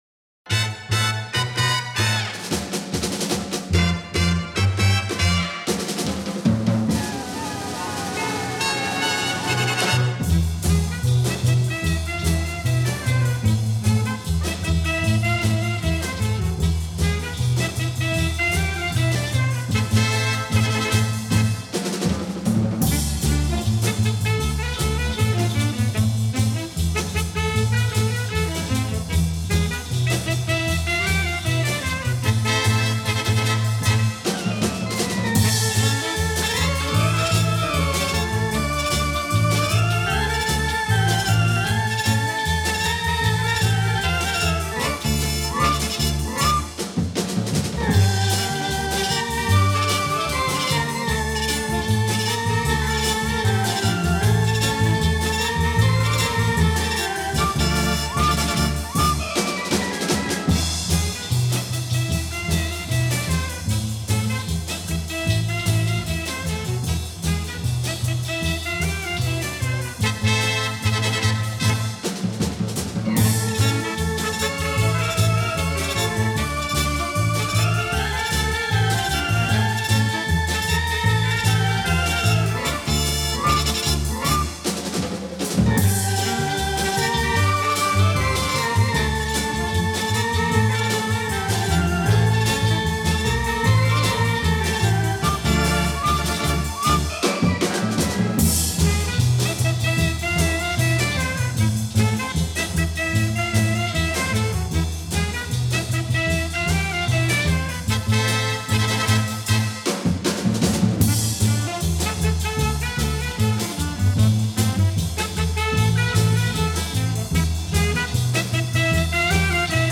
Жанр : Instrumental